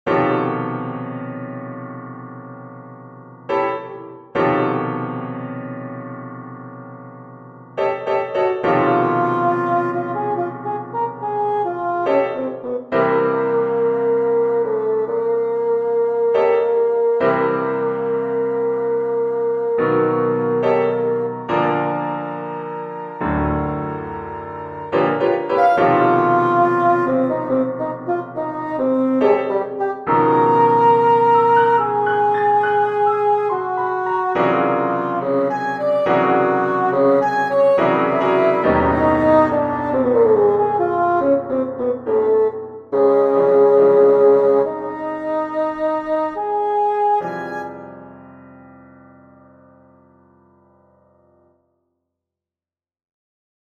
3 Melodies for Saxophone and Piano - Chamber Music
3 Melodies for Saxophone and Piano